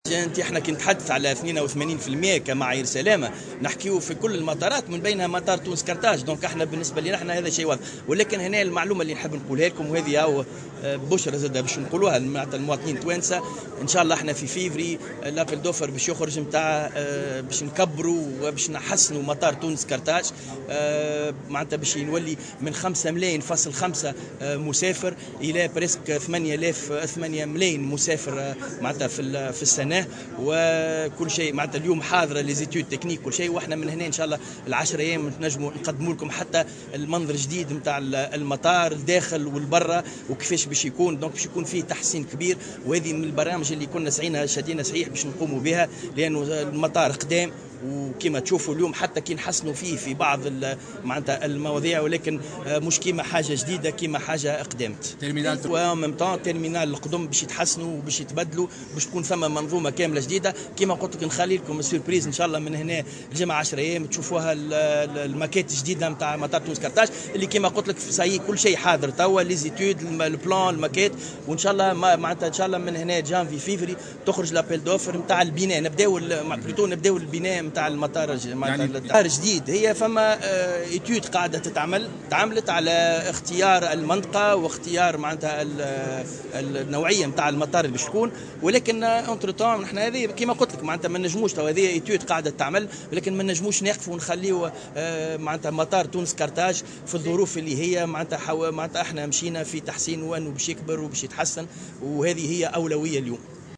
وتابع في تصريح لمراسلة "الجوهرة أف أم" على هامش اجتماع اقليمي حضره الوزير بالعاصمة، أن الدراسة جاهزة بخصوص هذه الأشغال وسيتم الإعلان عن مناقصة للبناء في شهر فيفري لتحسين مطار تونس قرطاج والقيام بأشغال توسعة للزيادة في طاقة استيعاب المطار الدولي من 5.5 ملايين حاليا إلى نحو 8 ملايين مسافر.